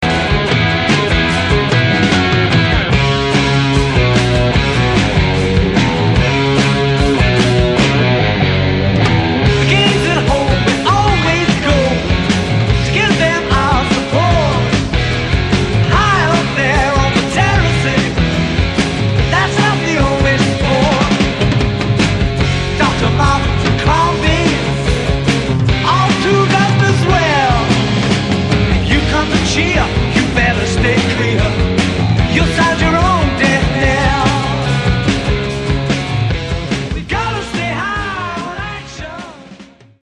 ドラム
ギター
ベース